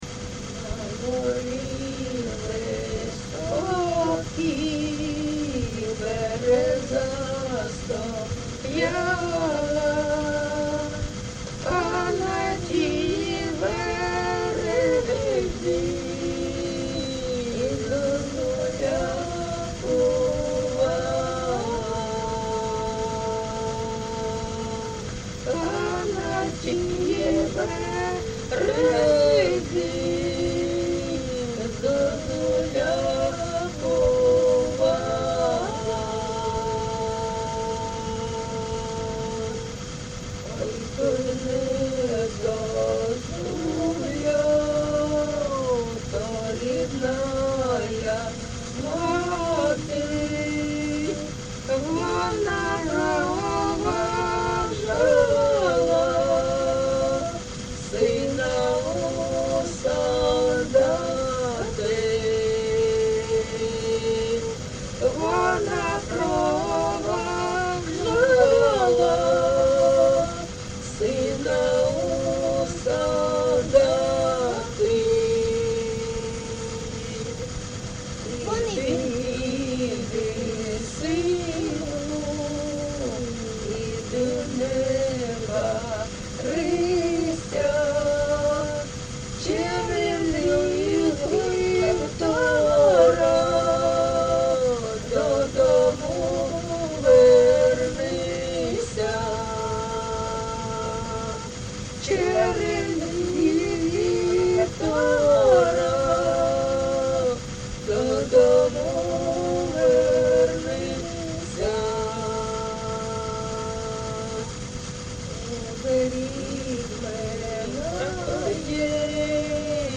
ЖанрПісні з особистого та родинного життя
Місце записус. Рідкодуб, Краснолиманський (Лиманський) район, Донецька обл., Україна, Слобожанщина